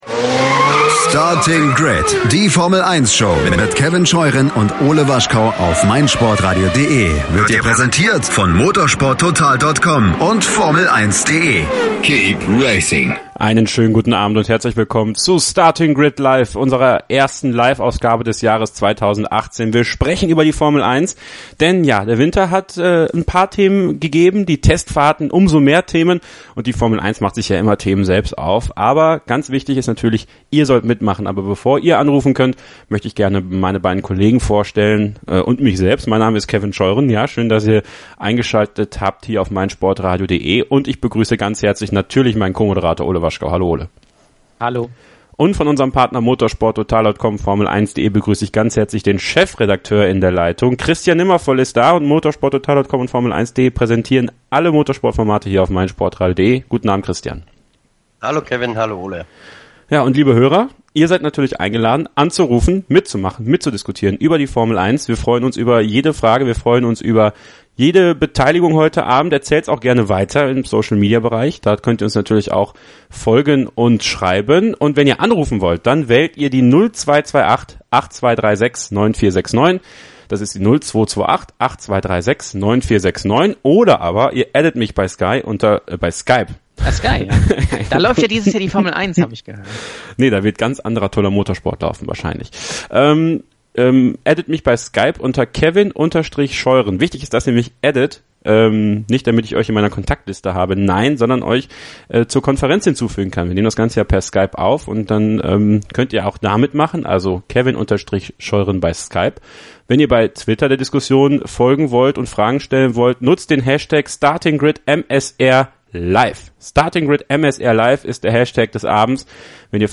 Einige von euch haben angerufen, es kamen viele Fragen per Mail oder via Social Media rein und es entwickelte sich ein lockerer Plausch über die bunten Themen der Formel 1. Zum Beispiel ging es darum, was rund um die F1-Strecken passieren muss, damit mehr Leute hinkommen.